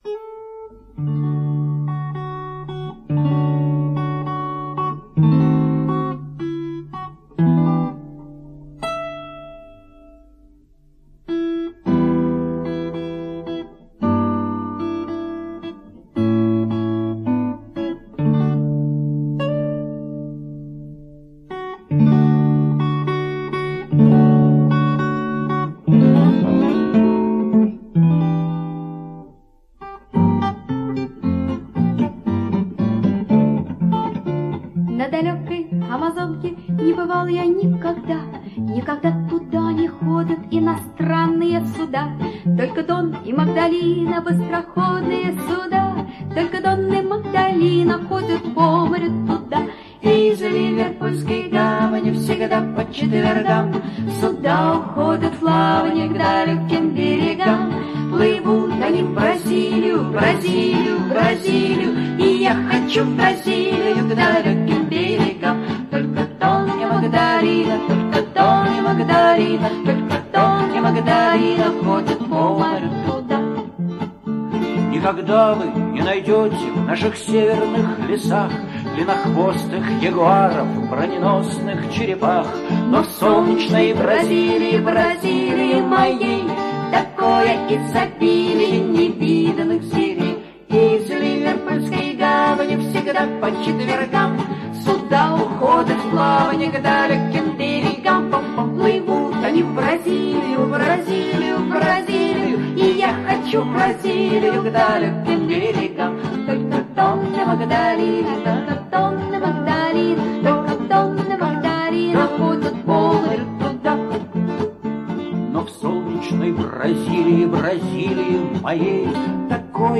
написана в традициях советской бардовской песни